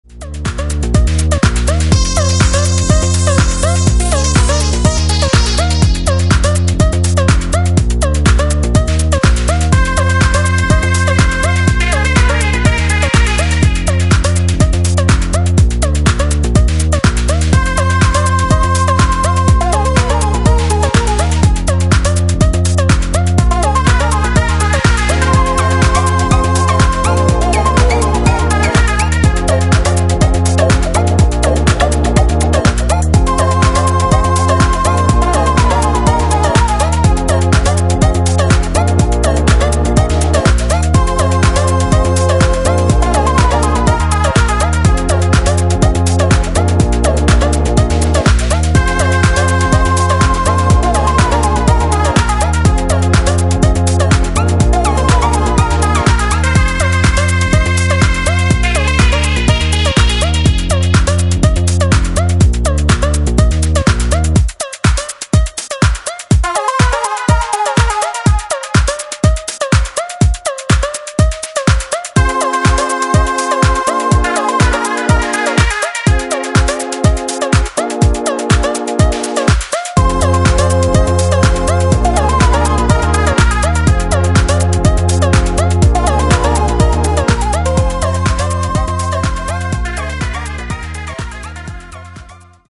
ジャンル(スタイル) NU DISCO / ELECTRONICA / BALEARICA